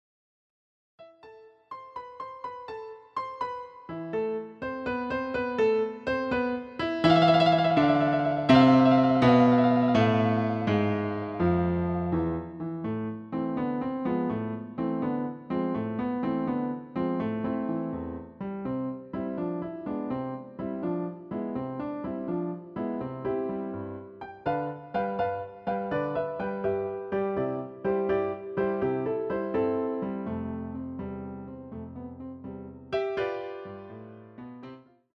CD quality digital audio Mp3 file
using the stereo sampled sound of a Yamaha Grand Piano.